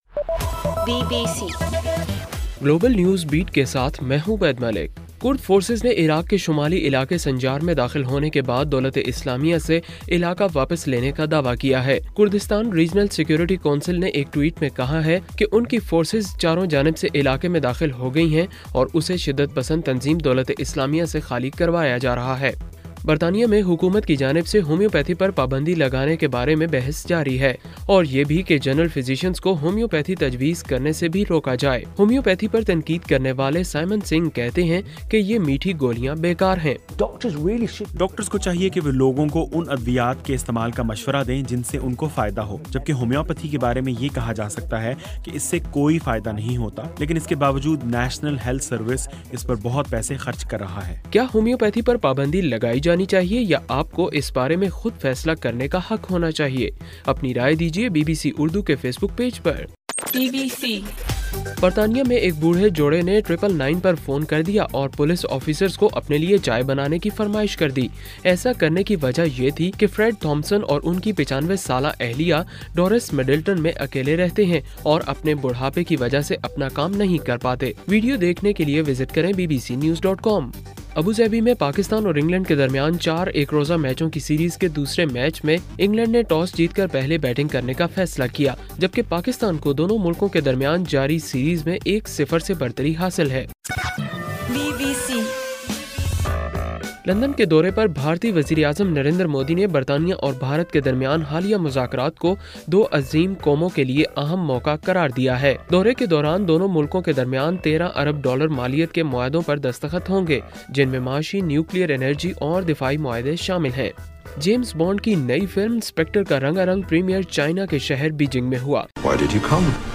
نومبر 13: رات 8 بجے کا گلوبل نیوز بیٹ بُلیٹن